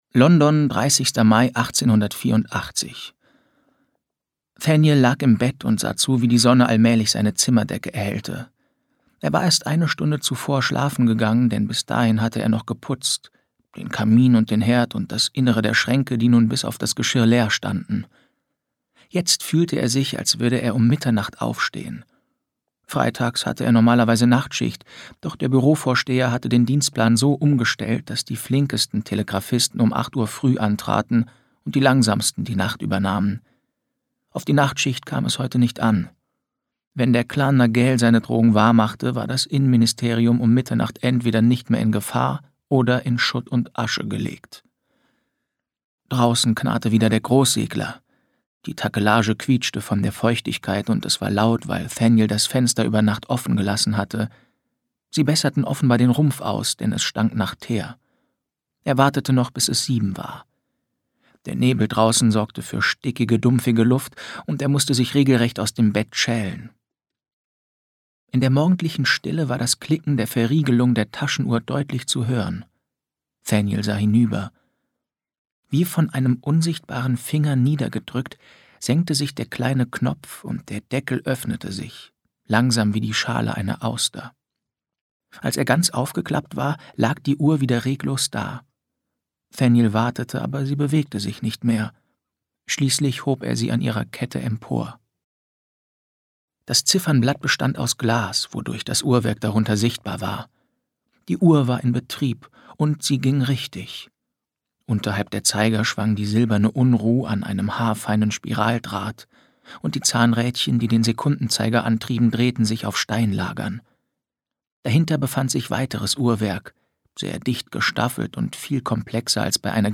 2021 | Ungekürzte Lesung